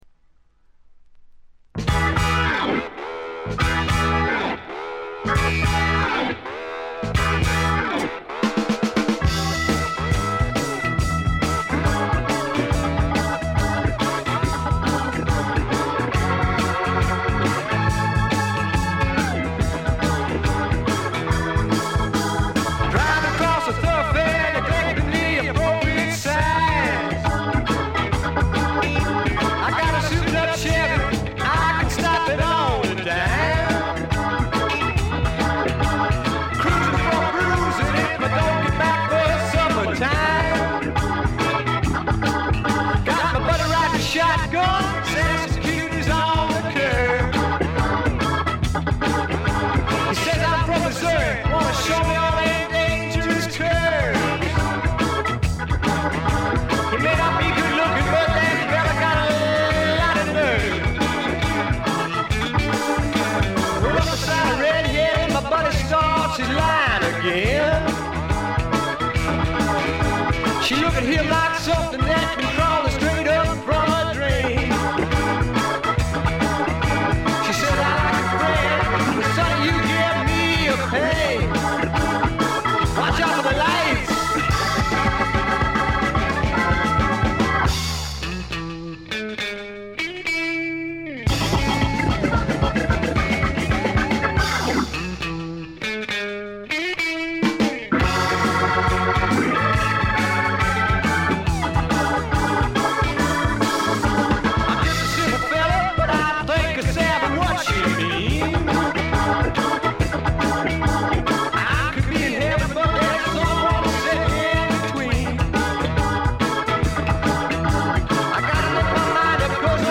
ジャケットはまるで激渋の英国フォークみたいですが、中身はパブロック／英国スワンプの裏名盤であります。
カントリー風味、オールド・ロックンロールを元にスワンプというには軽い、まさに小粋なパブロックを展開しています。
試聴曲は現品からの取り込み音源です。